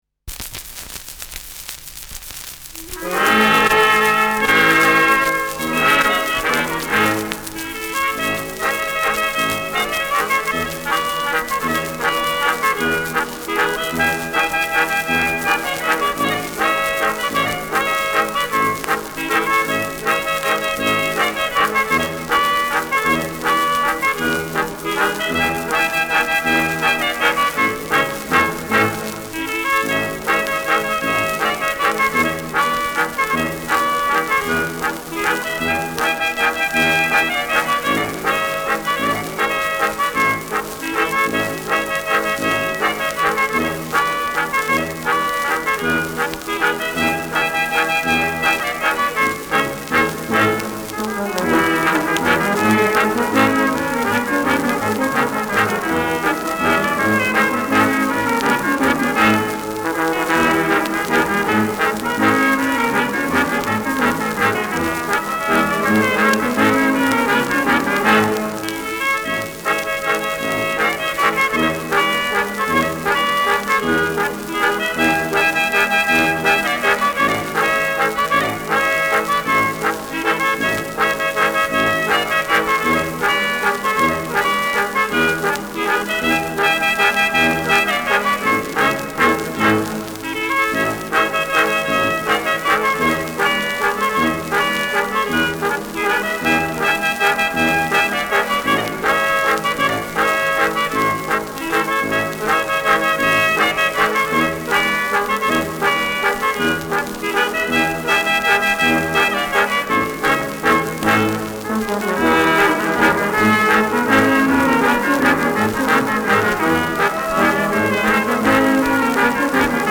Schellackplatte
präsentes Knistern : Tonarm „rutscht“ leicht im Anfangsakkord
Kapelle Jais (Interpretation)
[München] (Aufnahmeort)